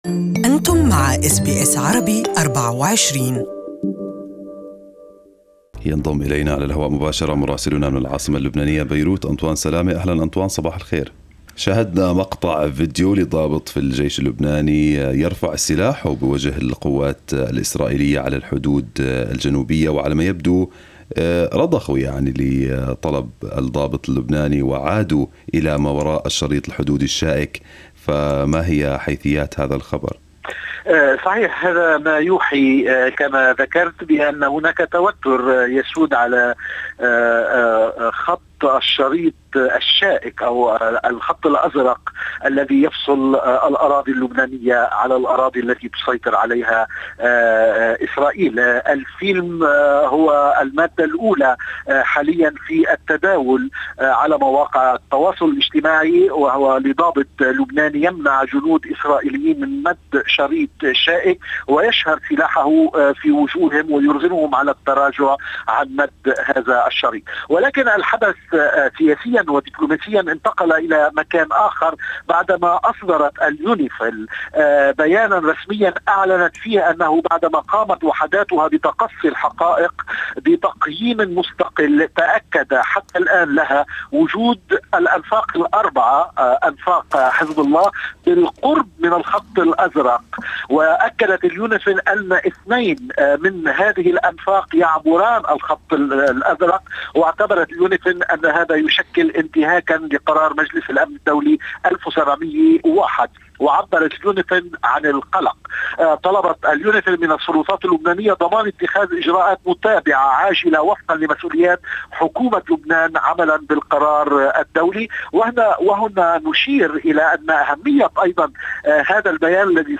Our correspondent in Lebanon has the details